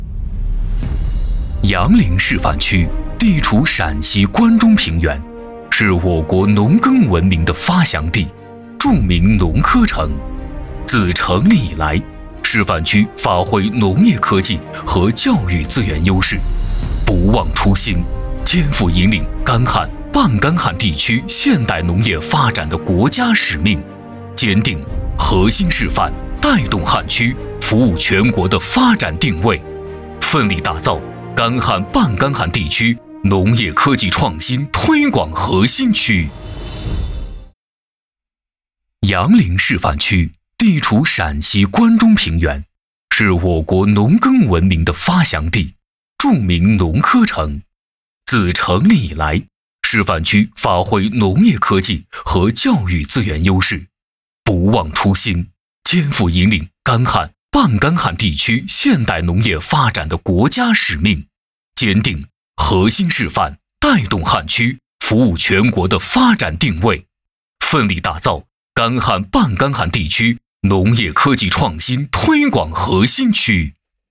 男16号配音师
配音演员自我介绍
专题片-男16-第十一届APEC技展会杨凌展团.mp3